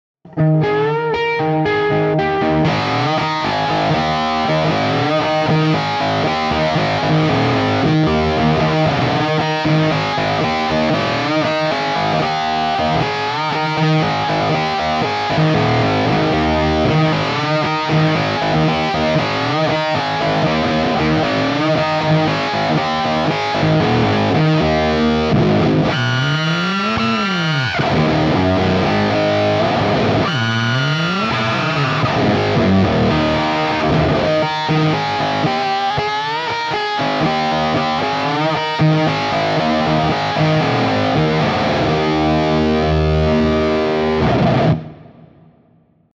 RINGMOD-esque (just octave)
Ring-Modesque.wav-just-OCT.mp3